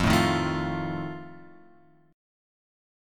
F7 chord {1 3 1 2 1 1} chord